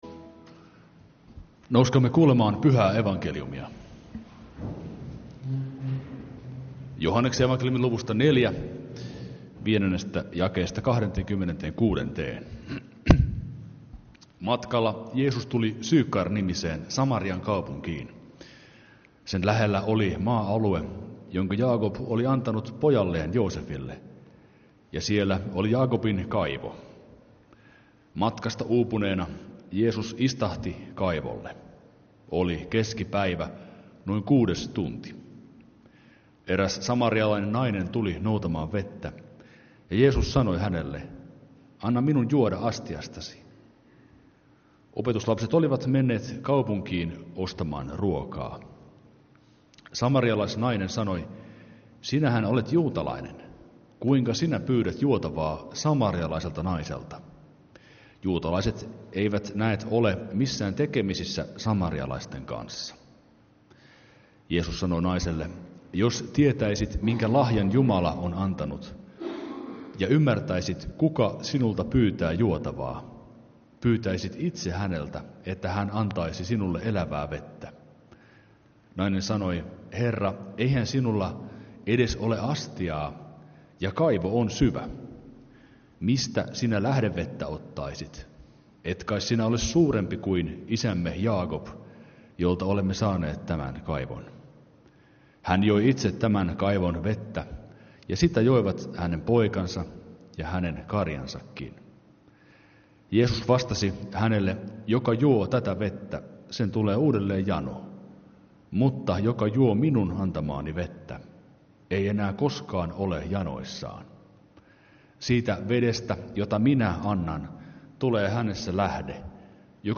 Kokoelmat: Lahden lutherin kirkon saarnat